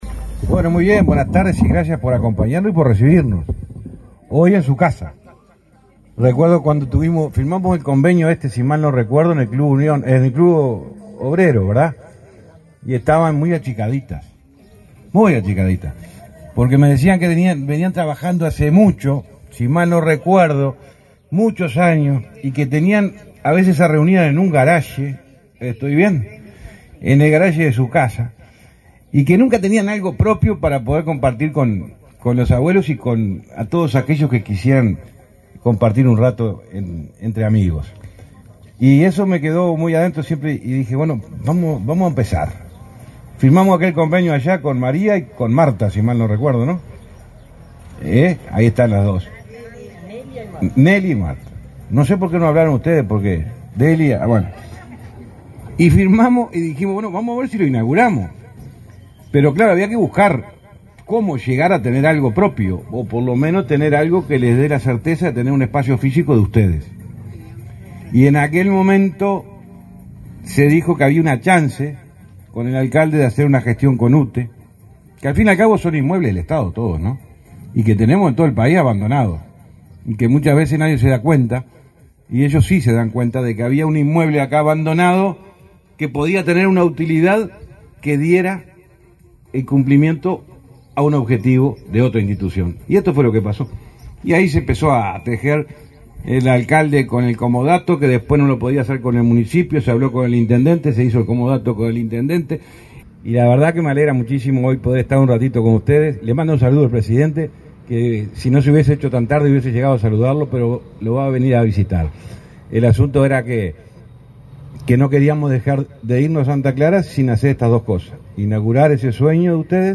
Palabras del ministro de Transporte, José Luis Falero, en Santa Clara del Olimar
El ministro de Transporte y Obras Públicas, José Luis Falero, participó, este 3 de octubre, en la inauguración de obras para la Asociación de